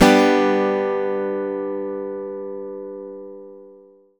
OVATION G-DU.wav